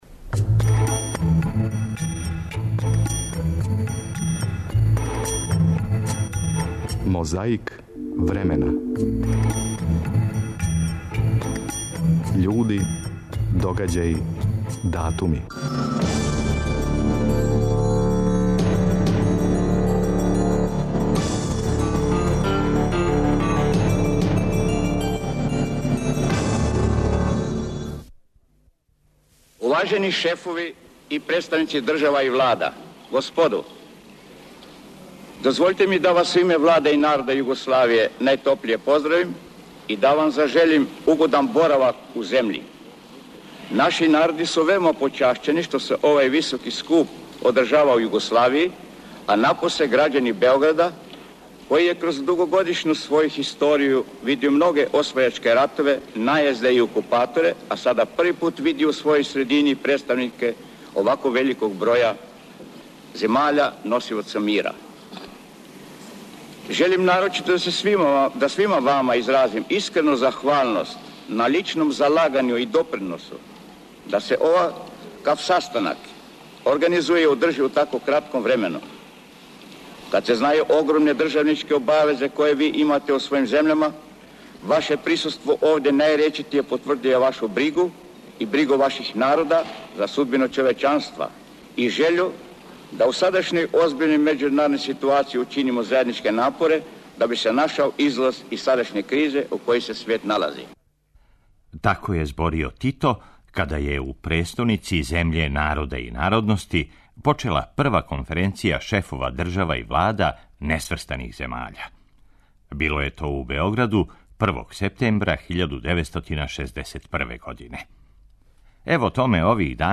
Почиње још једна велика борба против пилећег памћења - на почетку друг Тито. Чућете како је зборио када је у престоници земље народа и народности почела Прва конференција шефова држава и влада несврстаних земаља.